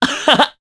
Kasel-Vox_Happy2_jp.wav